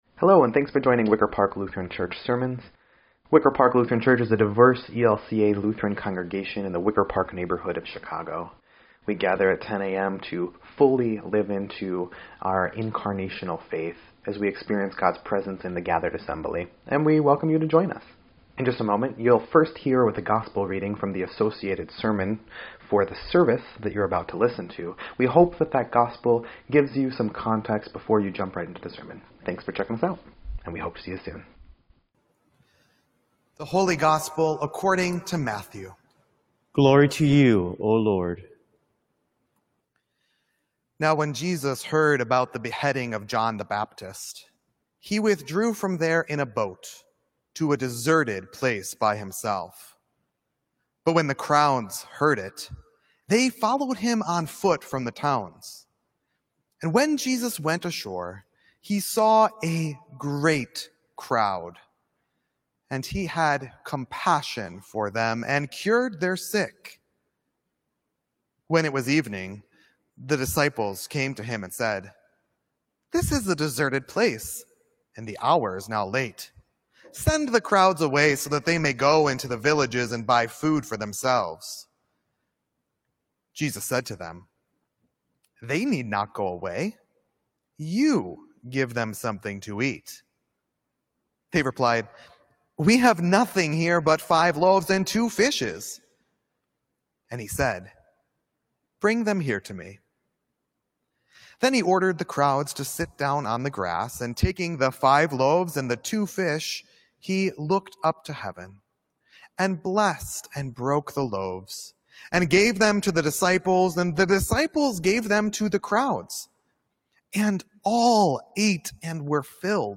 TAP9-8.2.20-Sermon_EDIT.mp3